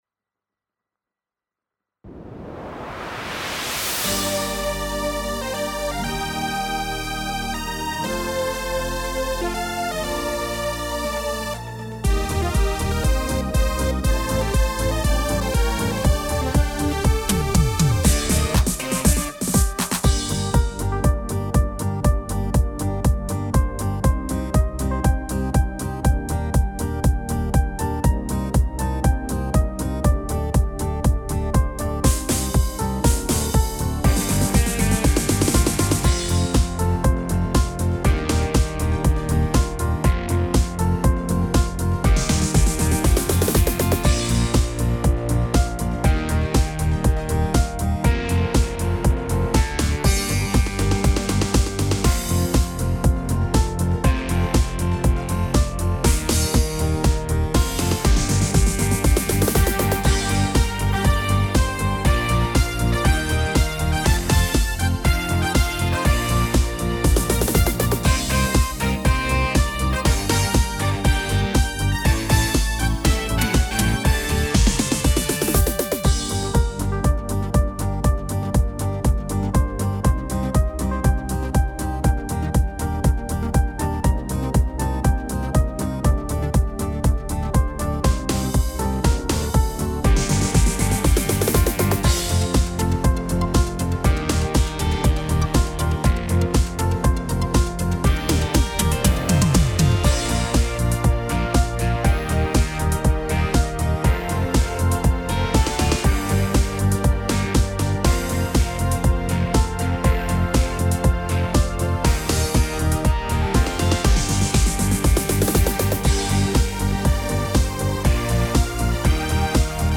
Tone Tốp (Gm)
•   Beat  03.